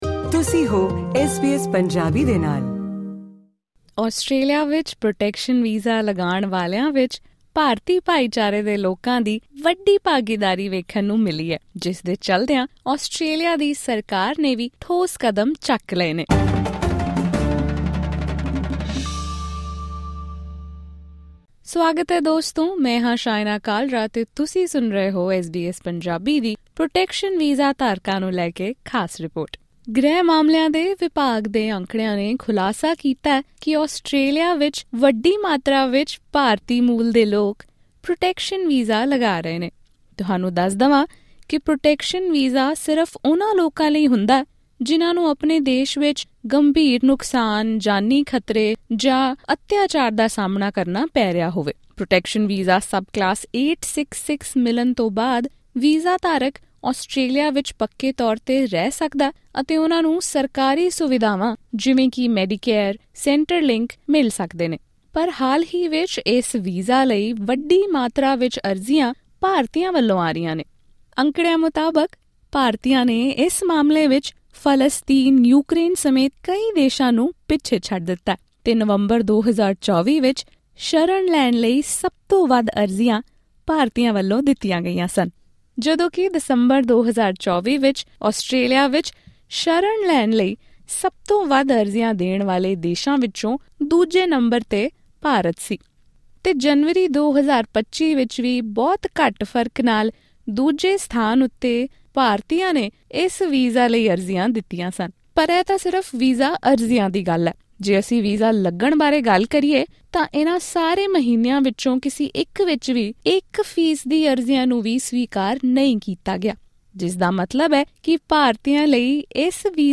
Experts fear misuse of visa and misguidance. Listen to the full report in this podcast.